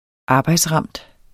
Udtale [ ˈɑːbɑjdsˌʁɑmˀd ]